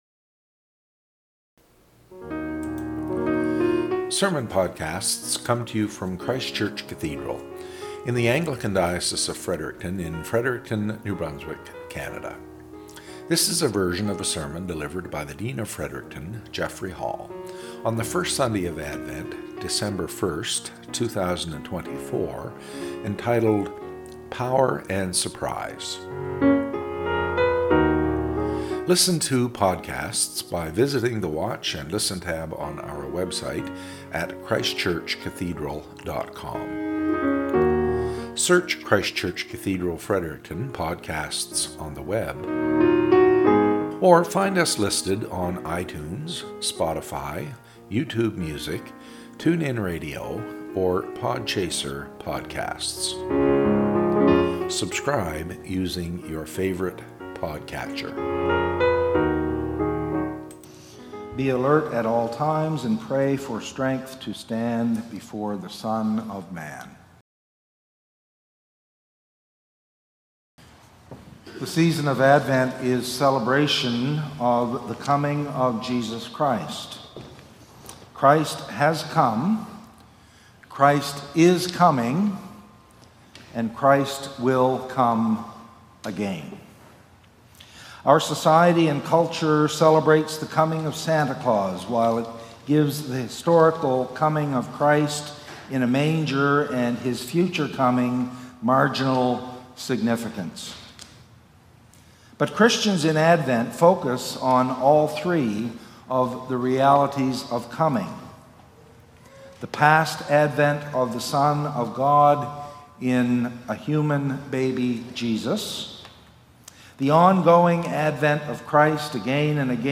Podcast from Christ Church Cathedral Fredericton
SERMON - "Power and Surprise"